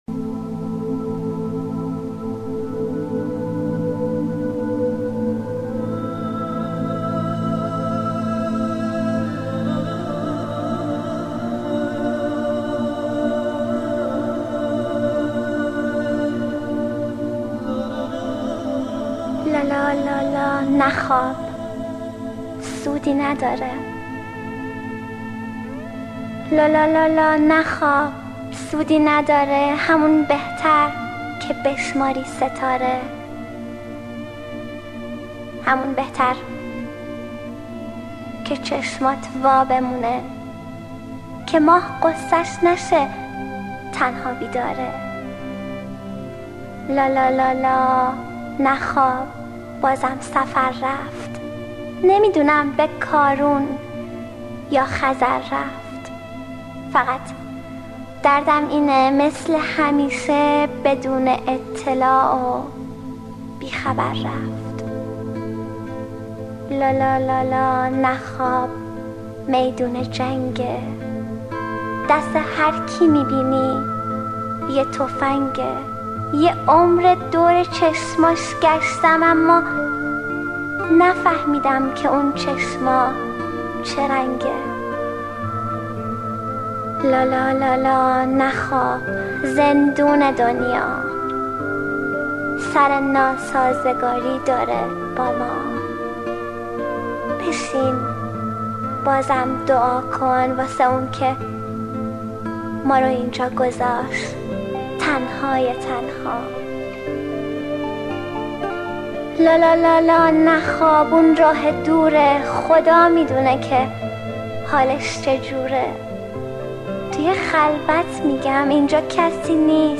دانلود دکلمه لالایی با صدای مریم حیدرزاده
گوینده :   [مریم حیدرزاده]